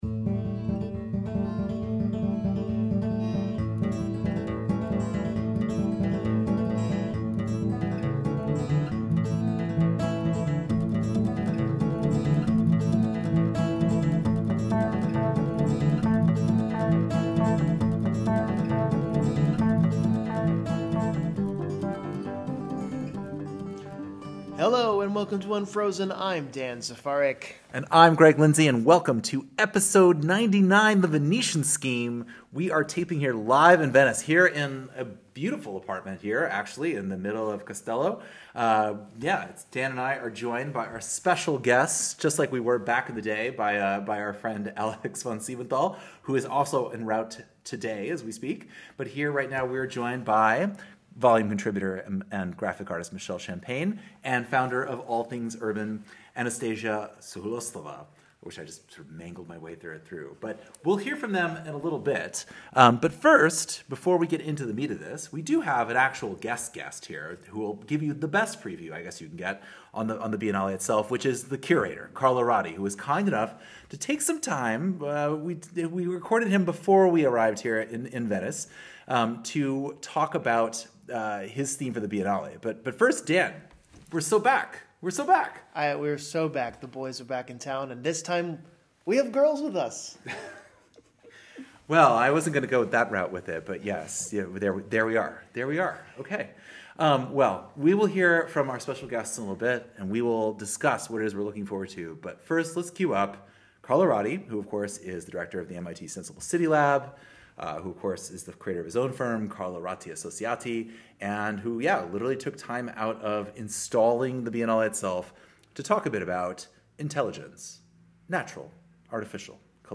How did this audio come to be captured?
The Unfrozen squad descends on Venice to experience inperson the full blunt force of the Biennale.